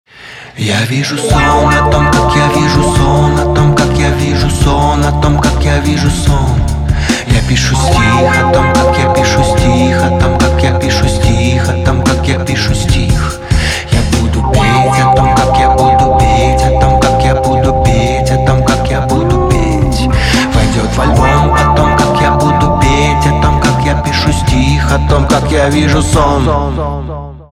инди
гитара , барабаны